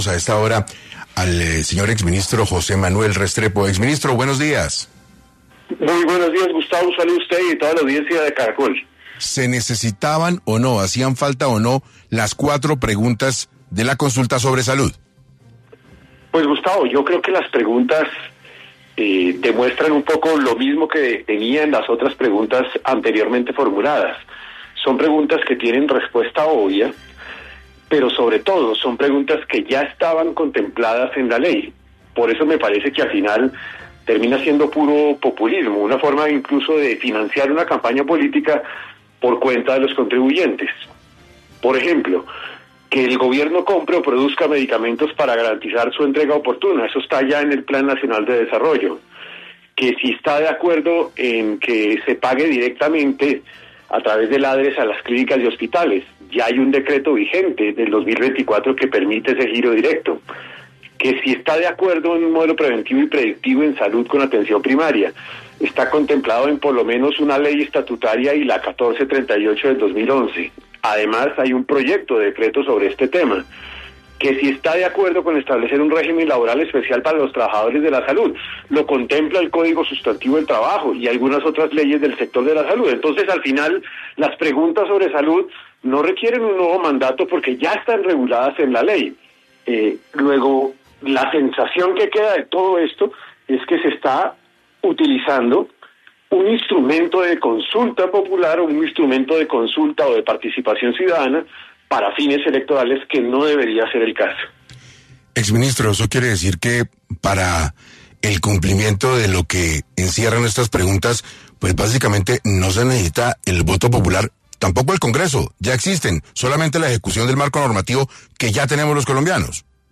En entrevista con 6AM de Caracol Radio el exministro de Hacienda José Manuel Restrepo aseguró que las cuatro nuevas preguntas ya están contempladas en la ley, por lo que esta consulta extendida con asuntos sanitarios termina siendo un mecanismo para financiar una campaña política con recursos públicos: